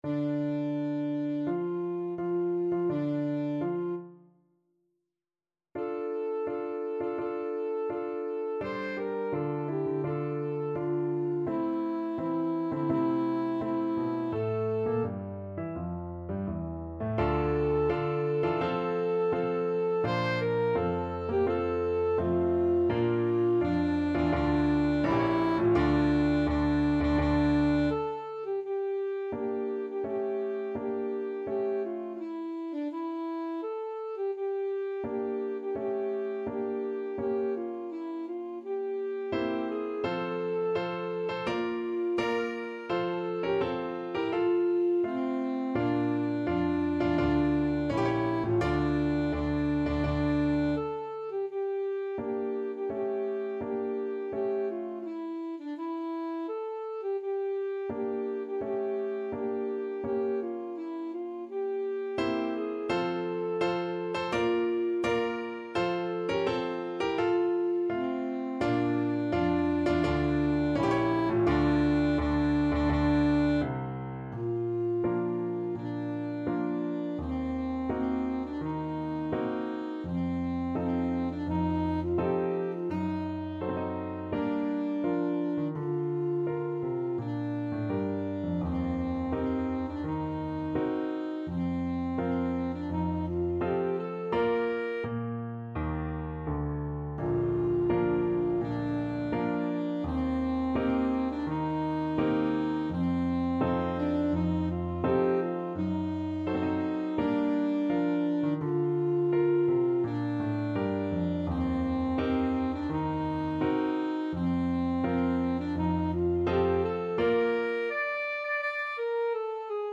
Classical Chopin, Frédéric Marche funebre, Op.72 No.2 (Trauermarsch) Alto Saxophone version
Alto Saxophone
D minor (Sounding Pitch) B minor (Alto Saxophone in Eb) (View more D minor Music for Saxophone )
4/4 (View more 4/4 Music)
Tempo di Marcia =84
Classical (View more Classical Saxophone Music)